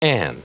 Prononciation du mot an en anglais (fichier audio)
Prononciation du mot : an